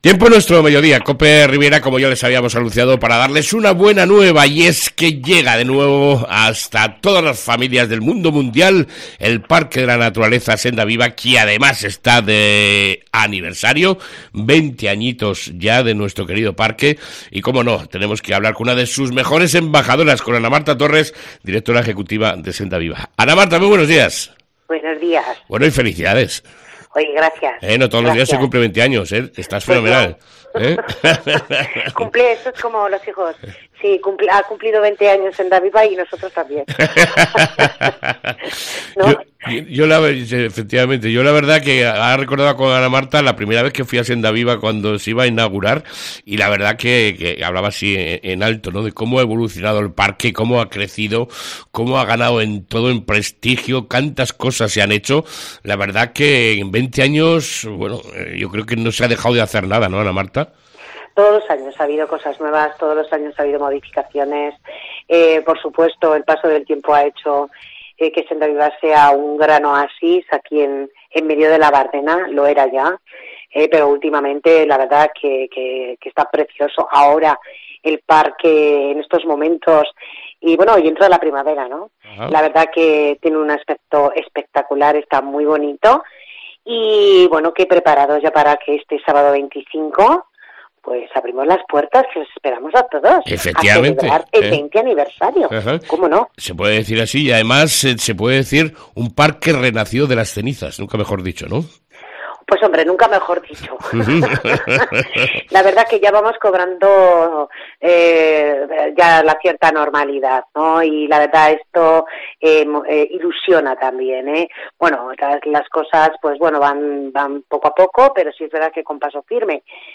ENTREVISTA CON SENDAVIVA